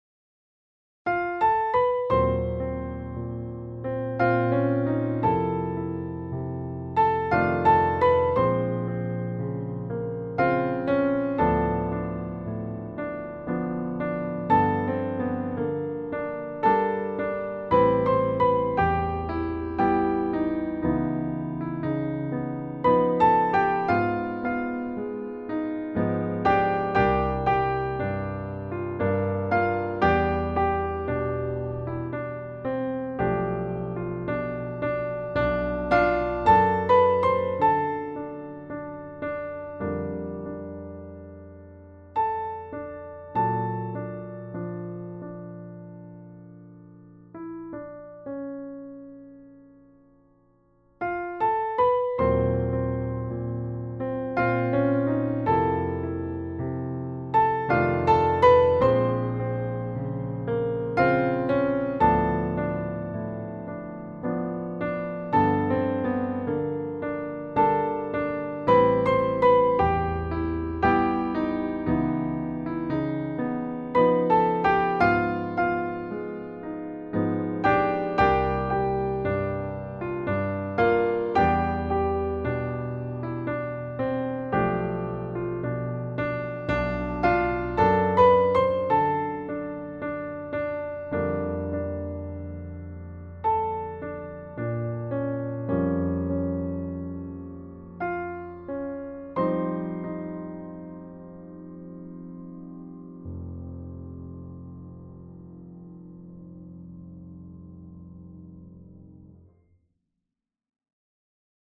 Piano duet 1st part easy